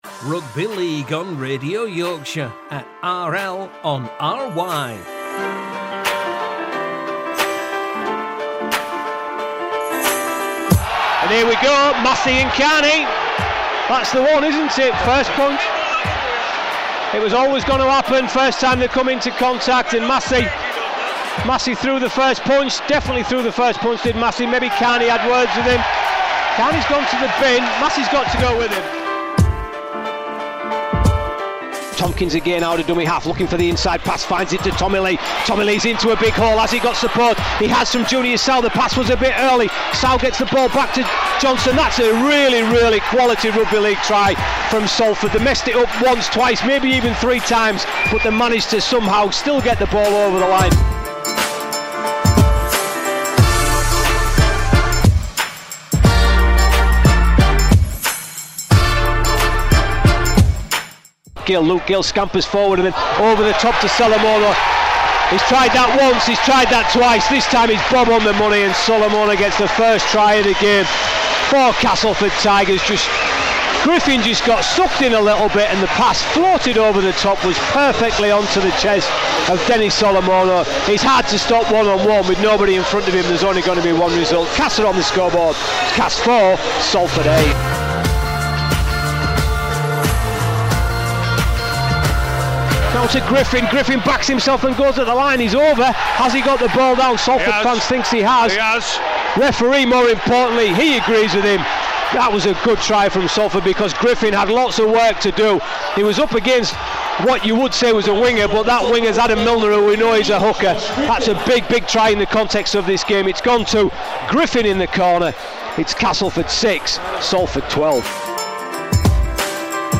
were on commentary duty as the Castleford Tigers were defeated at The Jungle against Salford.